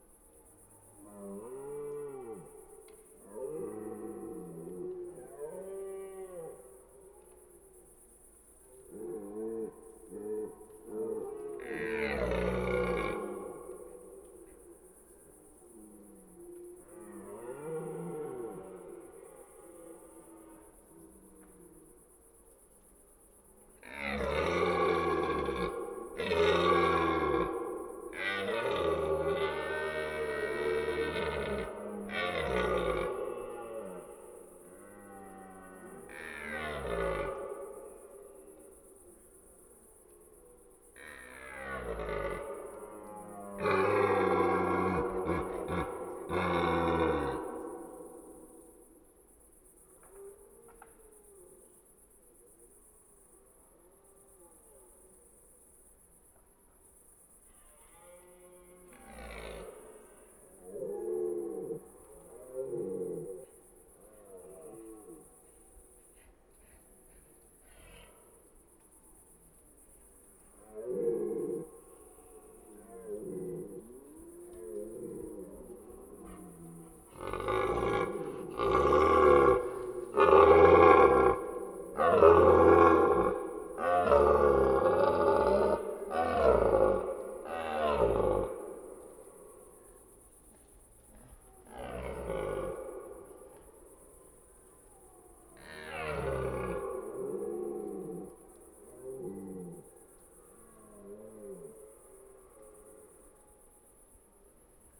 Ce troisième titre de la collection « Galets sonores » regroupe une série d’enregistrements sur le thème du brame du Cerf élaphe, collectés dans différents massifs forestiers de France (Vosges, Loiret, Lozère...) entre 2011 et 2023.